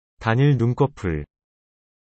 韓国で一重まぶたを表す言葉は「단일 눈꺼풀（タニヌンコップ）」です。